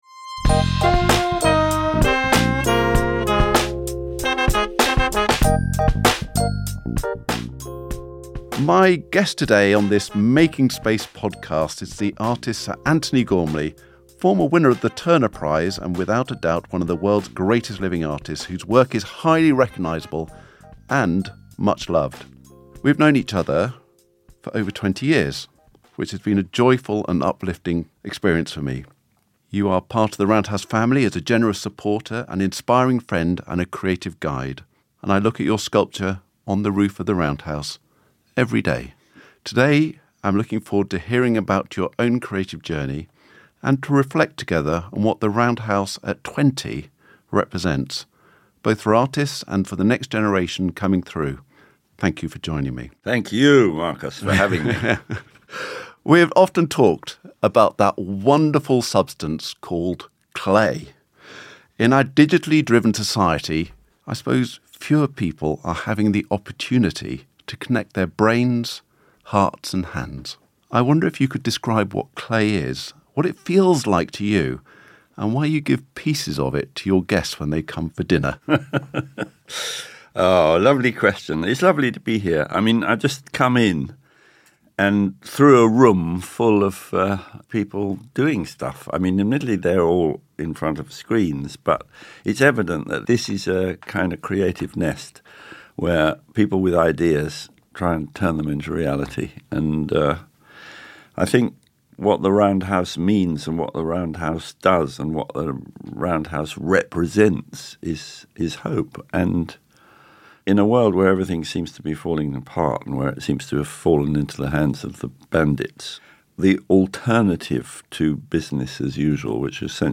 Making Space is a six-episode series, featuring intimate conversations with leading artists and thinkers connected to the Roundhouse.